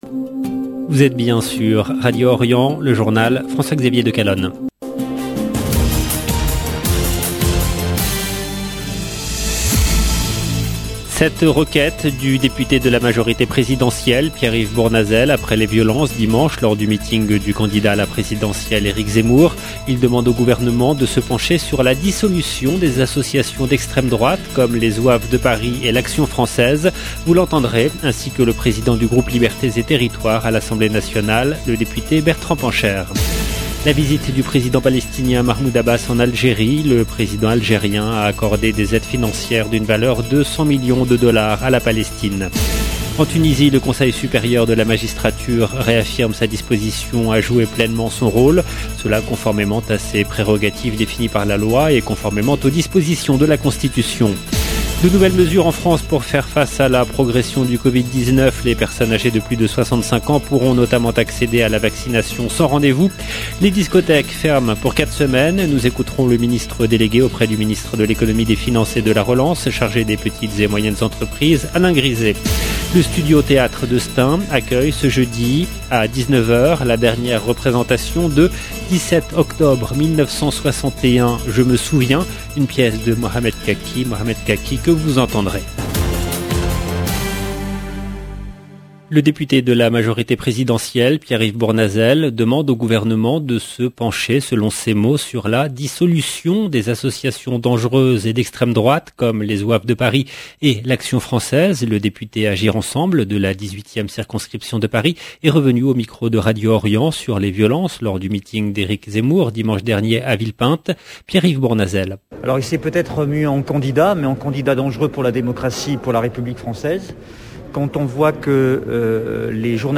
LB JOURNAL EN LANGUE FRANÇAISE
Vous l’entendrez ainsi que le président du groupe Libertés et territoires à l’Assemblée nationale Bertrand Pancher. La visite du président palestinien Mahmoud abbas en Algérie.